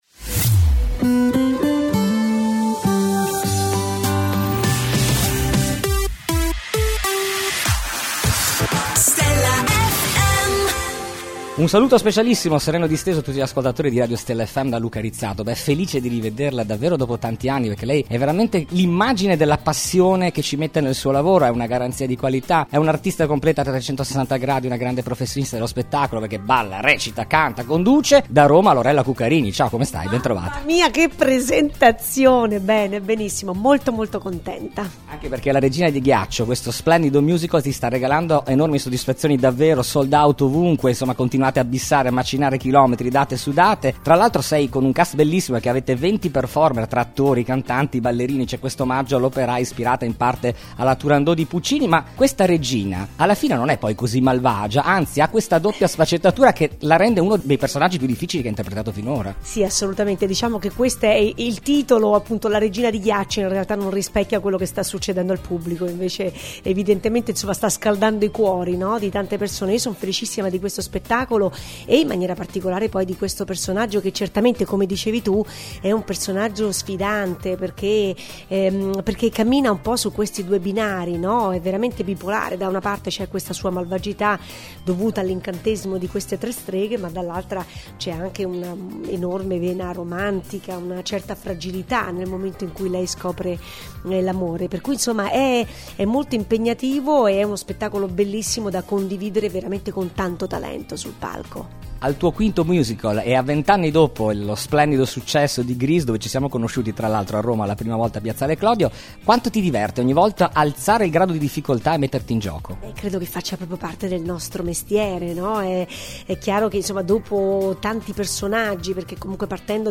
Intervista esclusiva dell’inviato per Stella Fm a Lorella Cuccarini.